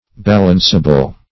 Balanceable \Bal"ance*a*ble\, a. Such as can be balanced.